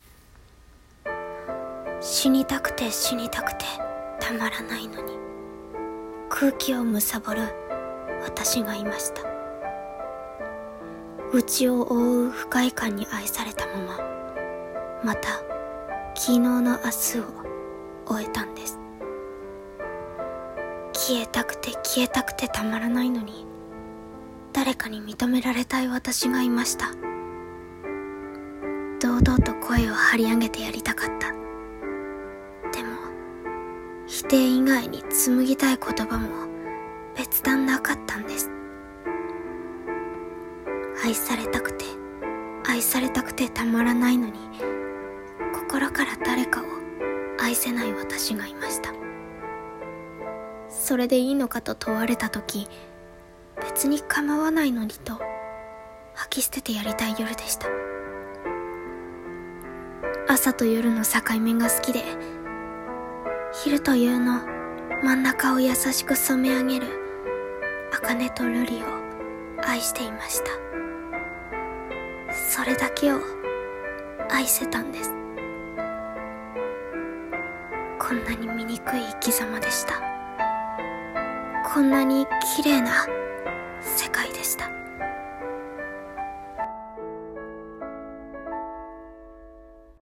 一人声劇】茜と瑠璃